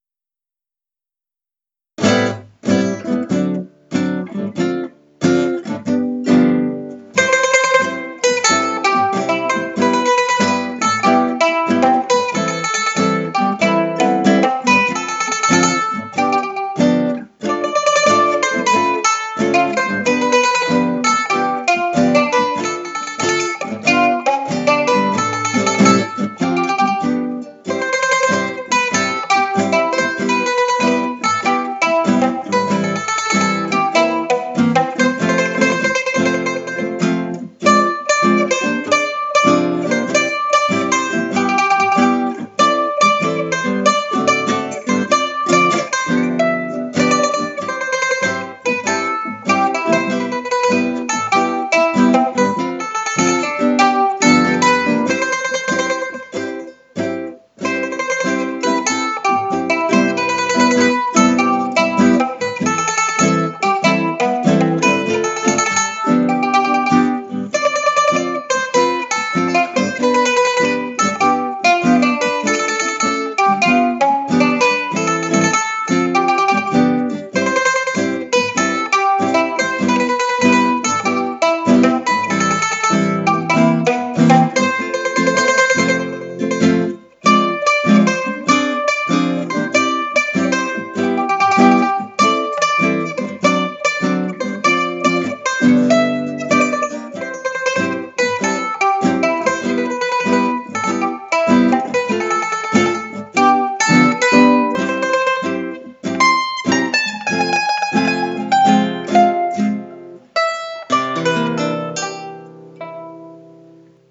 Cerimonia Civile